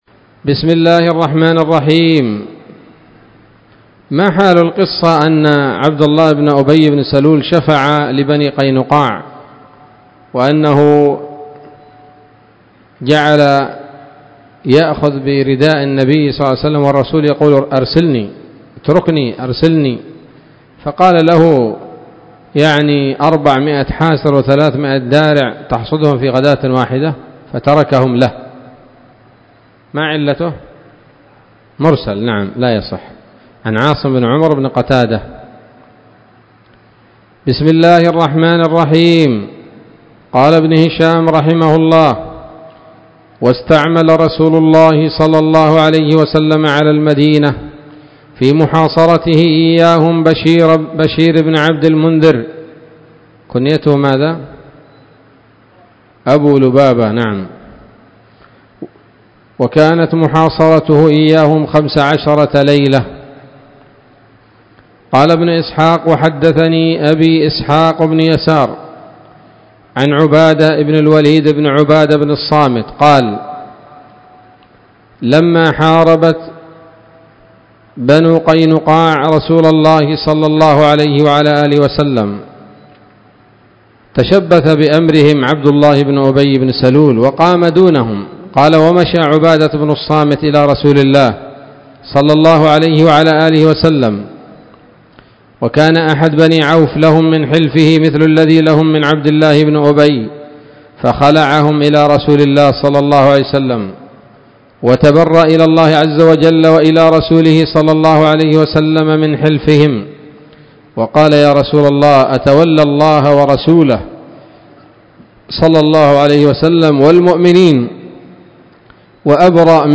الدرس التاسع والأربعون بعد المائة من التعليق على كتاب السيرة النبوية لابن هشام